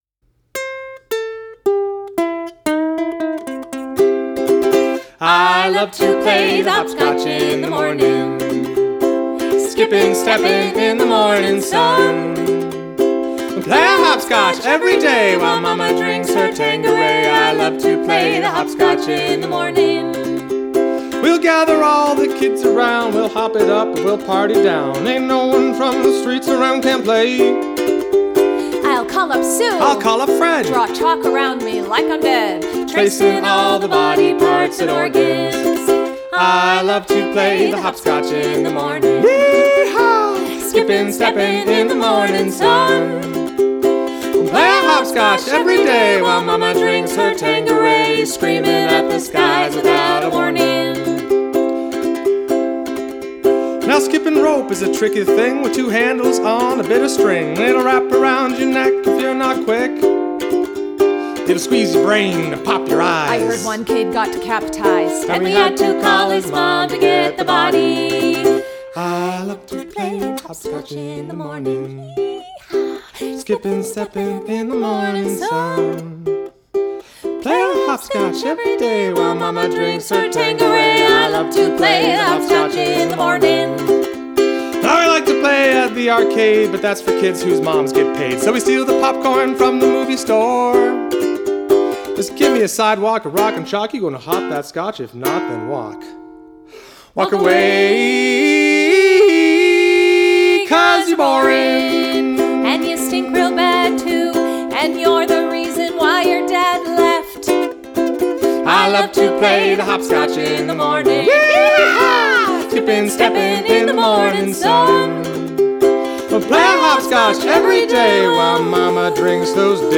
These are demos y'all.